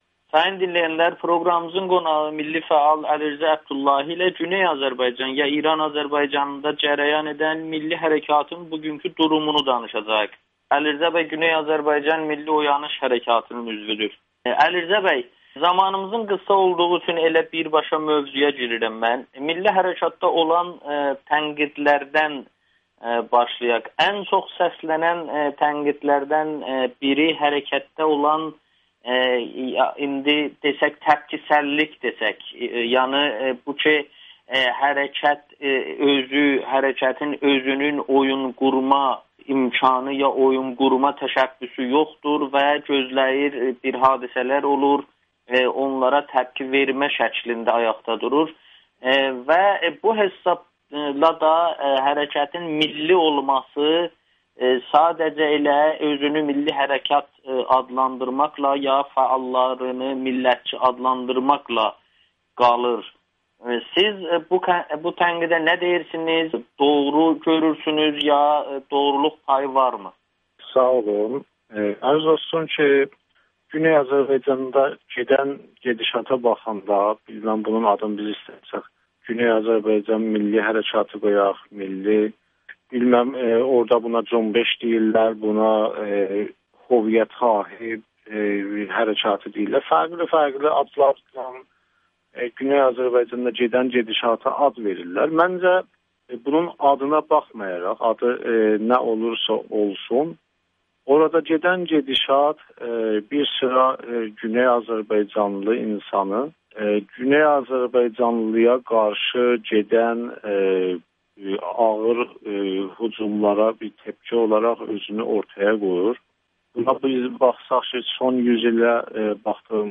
Güneydəki hərəkat hücumlara qarşı bir təpkidir [Audio-Müsahibə]